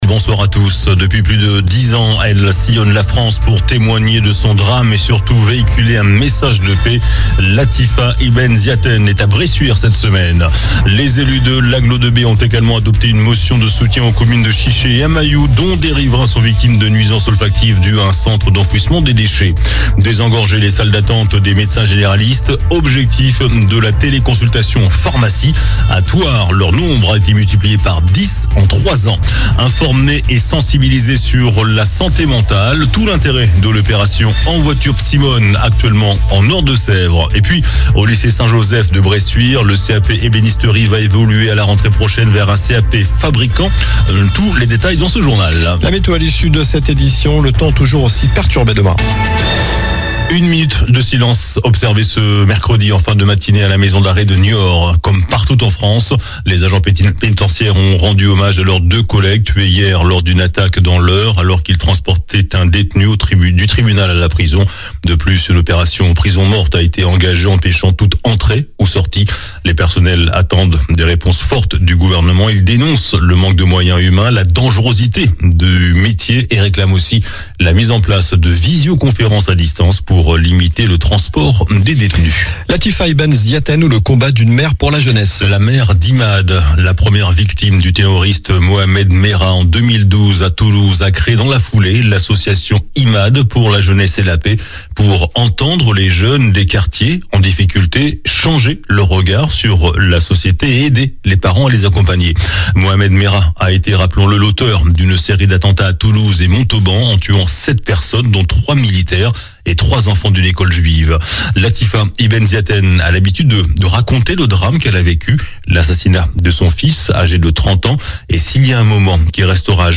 JOURNAL DU MERCREDI 15 MAI ( SOIR )